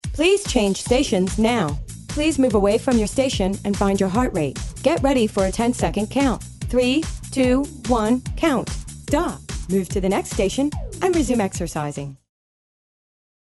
All our Cue CDs are studio recorded and work great on all 30 minute style fitness circuits.
Standard Cue: A basic Male or Female prompt to change stations.
The "previews" have music background for reference.
Std. Female Cue
CueCD-FemaleCue.mp3